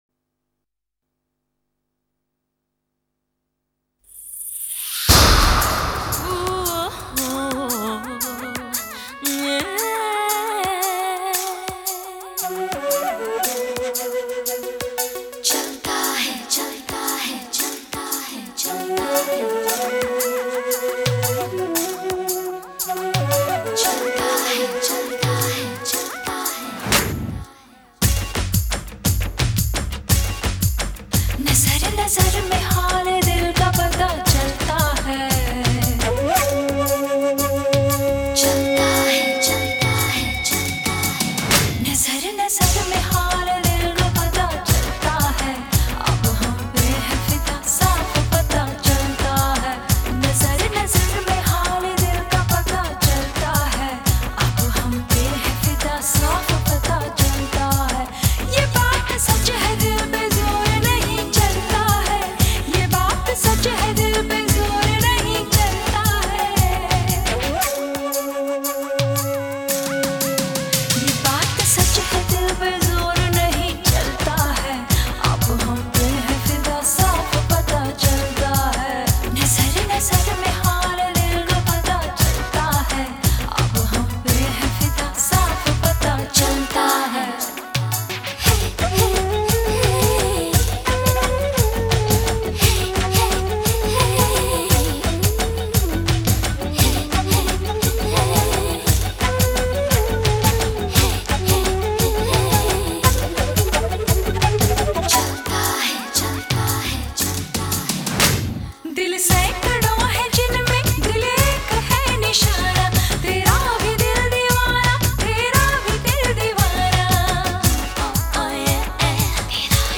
2. Bollywood MP3 Songs